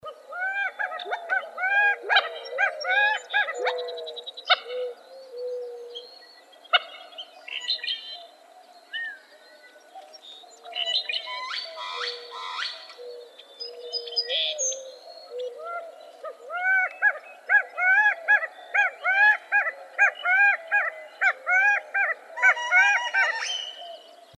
gambelsquail.wav